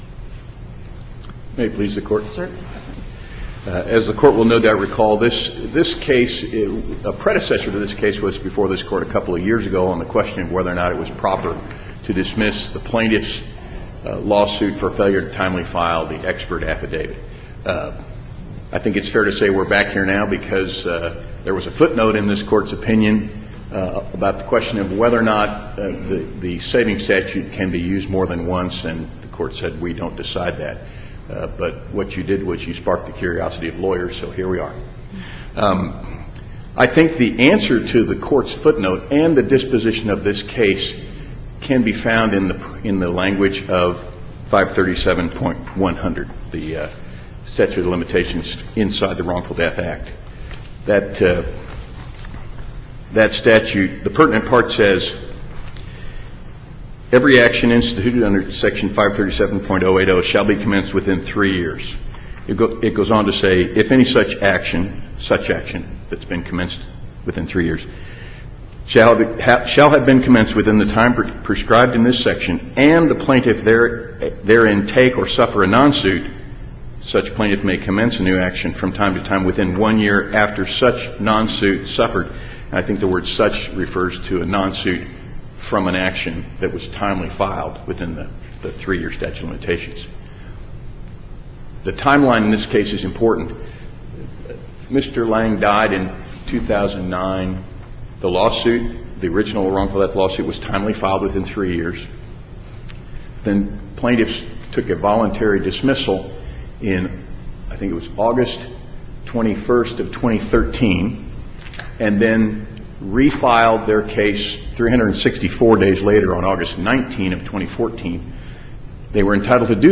MP3 audio file of oral arguments in SC95910